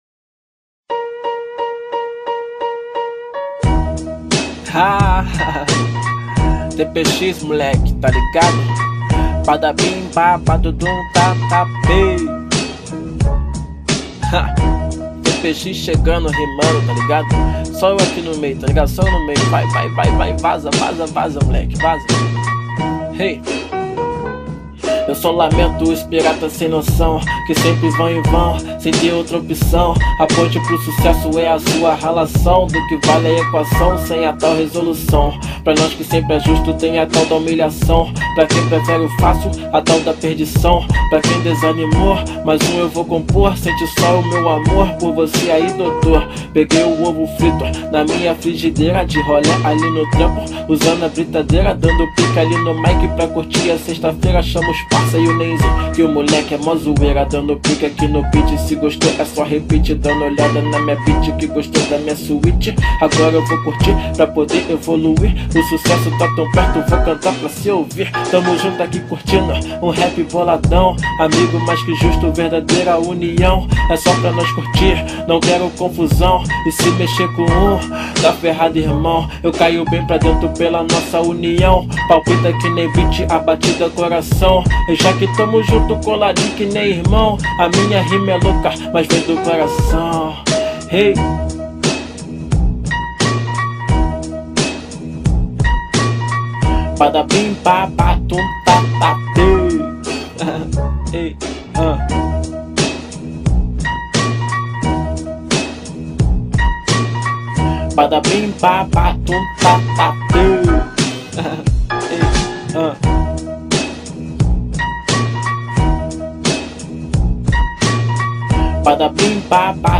Rap Nacional